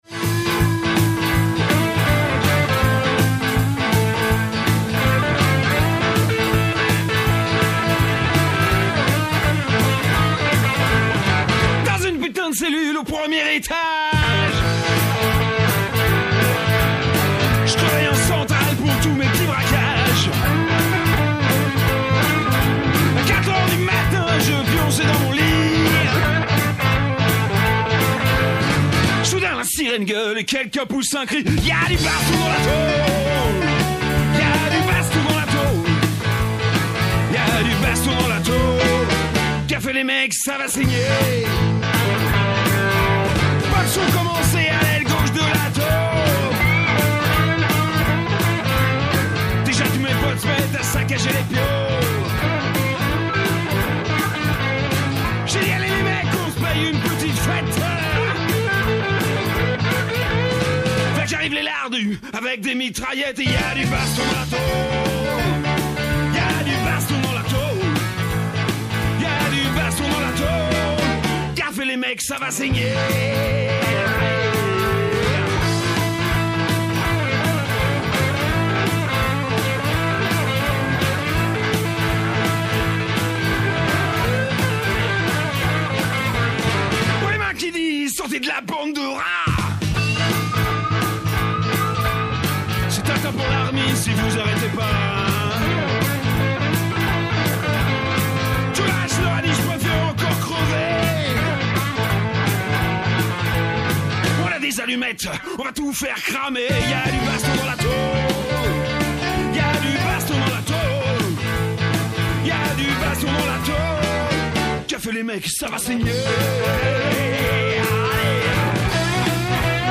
Émission radio l'Envolée du vendredi 4 avril 2025